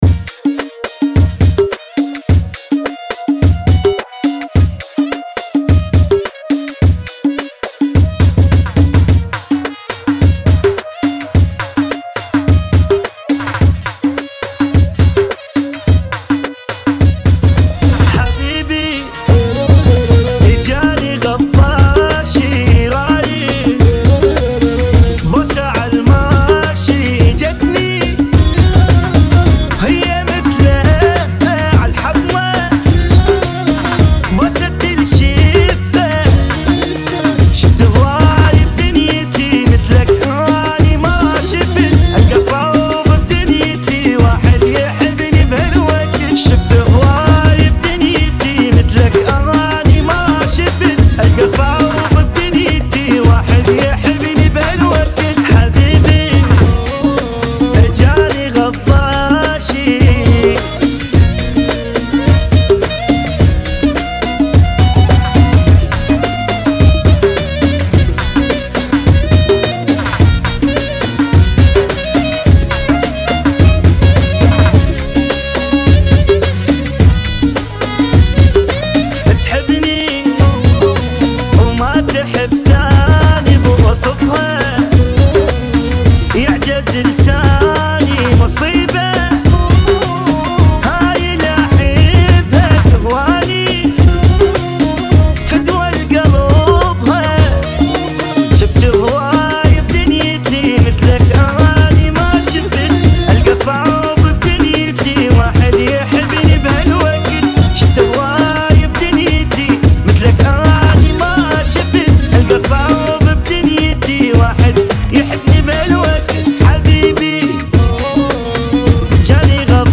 [ 75 Bpm ]